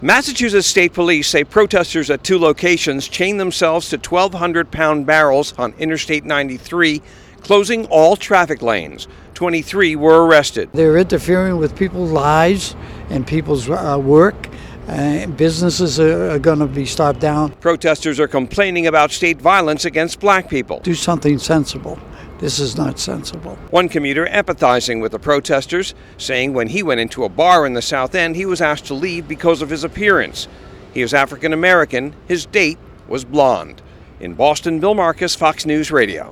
(BOSTON) JAN 15 – A MESSY COMMUTE SOUTH OF BOSTON MADE MESSIER ON INTERSTATE 93 WITH PROTESTERS CHAINING THEMSELVES TO BARRELS. FOX NEWS RADIO’S